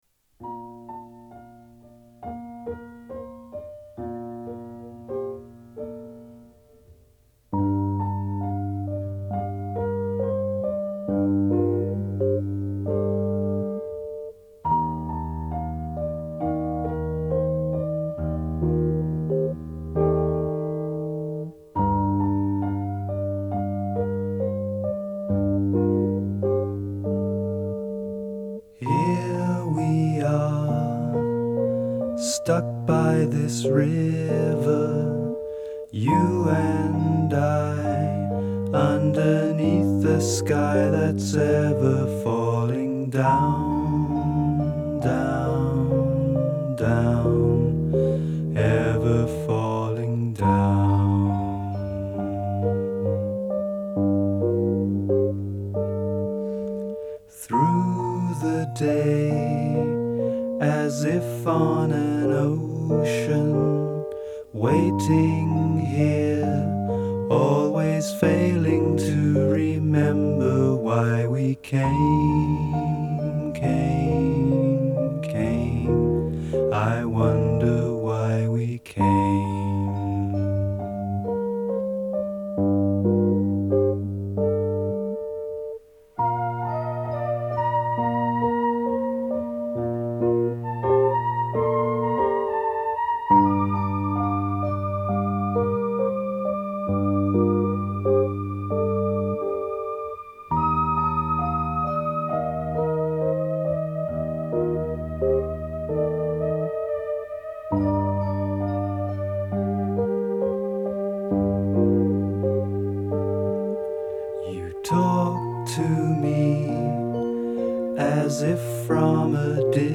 Art Pop Ambient
موسیقی آمبینت آرت پاپ